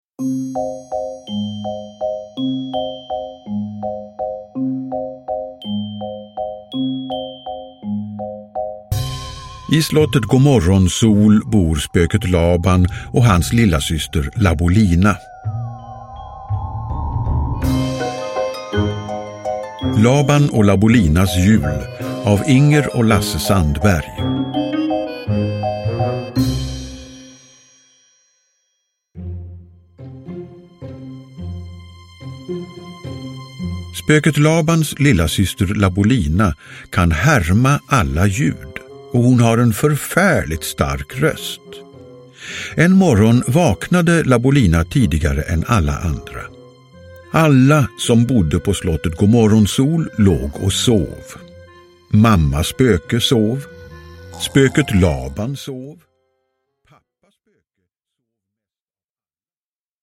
Labans och Labolinas jul – Ljudbok – Laddas ner